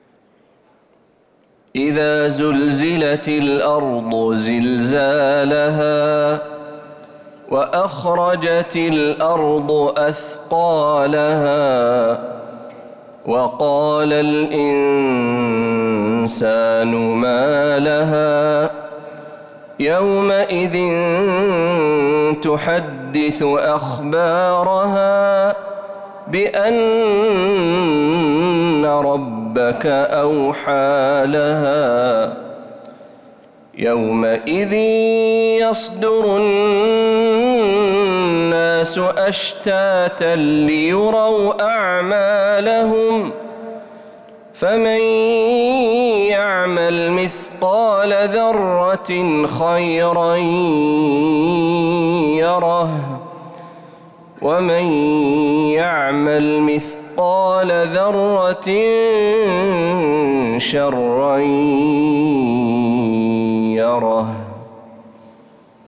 سورة الزلزلة كاملة من فجريات الحرم النبوي للشيخ محمد برهجي | رجب 1446هـ > السور المكتملة للشيخ محمد برهجي من الحرم النبوي 🕌 > السور المكتملة 🕌 > المزيد - تلاوات الحرمين